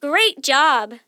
GreatJob.wav